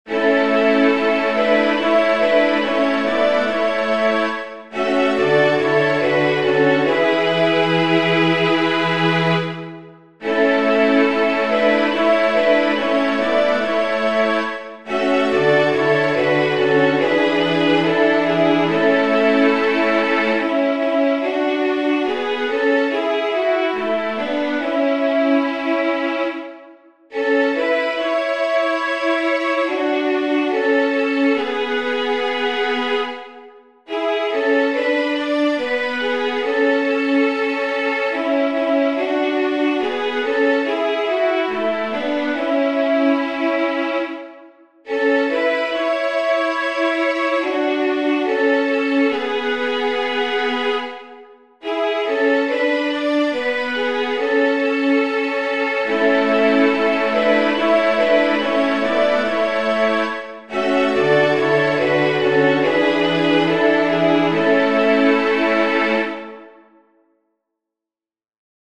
Antienne d'ouverture Téléchargé par